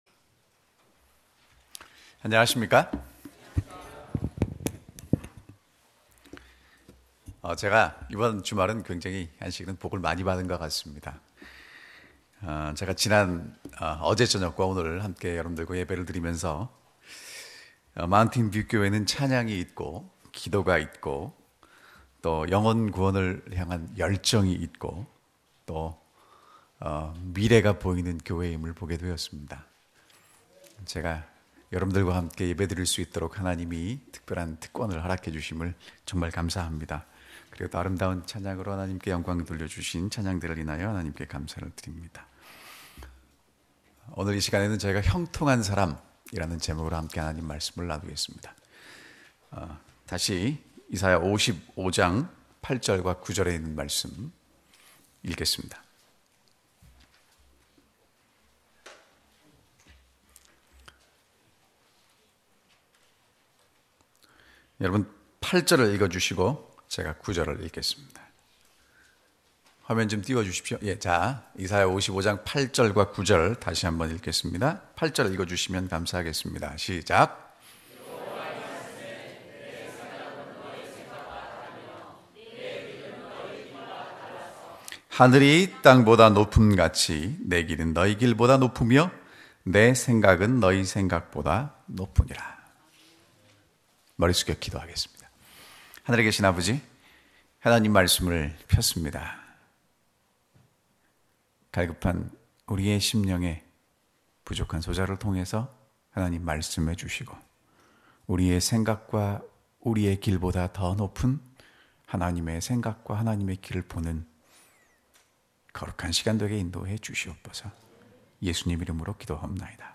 Other Sermon